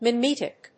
音節mi・met・ic 発音記号・読み方
/mɪméṭɪk(米国英語)/